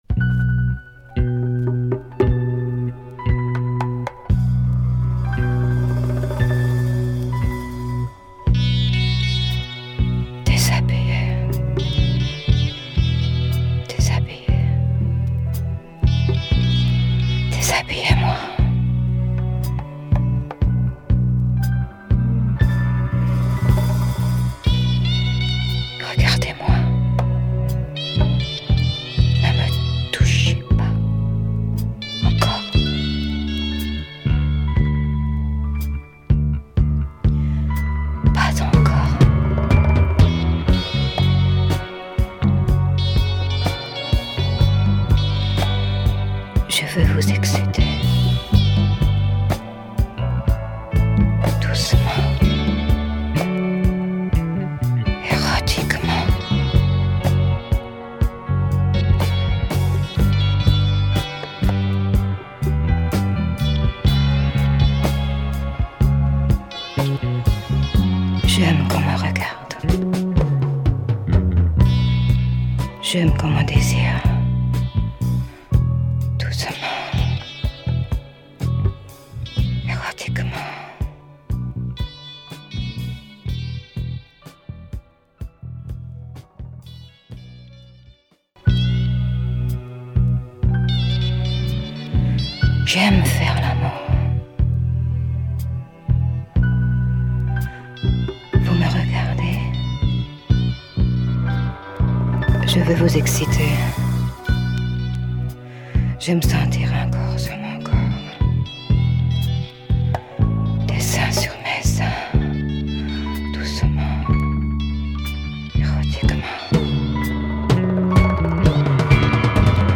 features some super deep and sensual groove